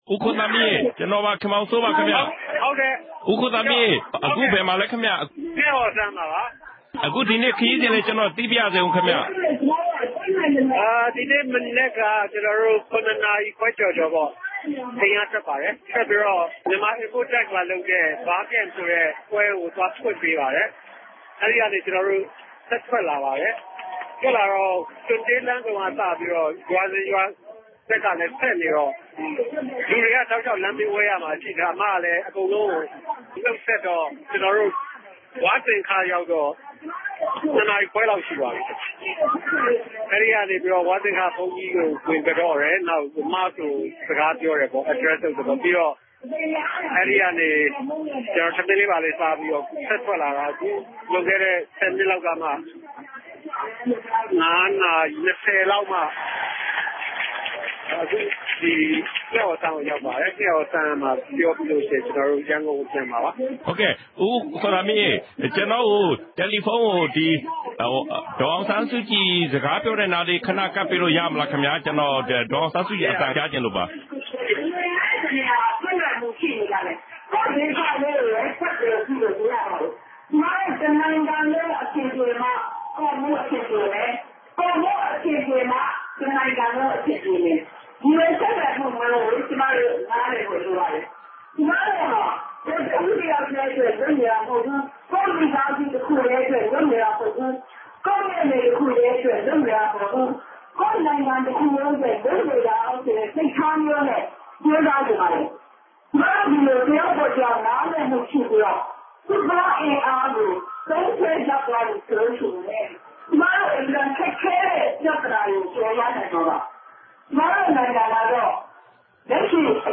ငှက်အော်စမ်းမှာ အဲဒီလို မိန့်ခွန်းပြောကြားနေစဉ်